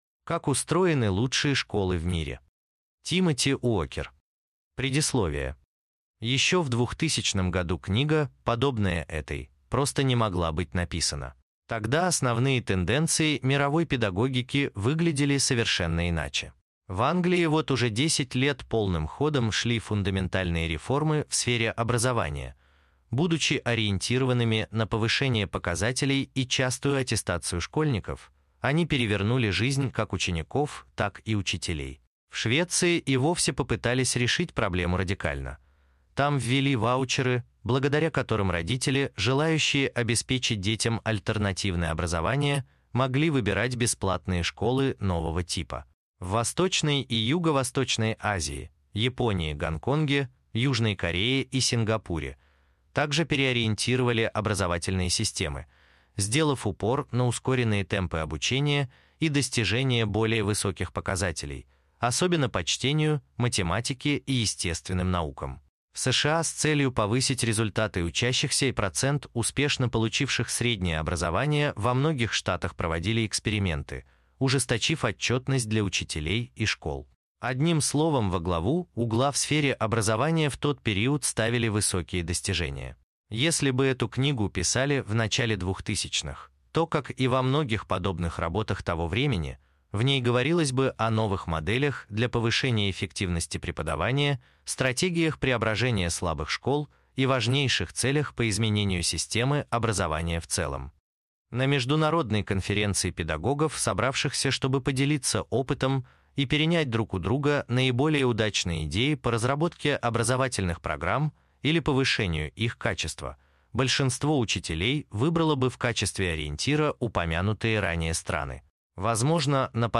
Аудиокнига Финская система обучения: Как устроены лучшие школы в мире | Библиотека аудиокниг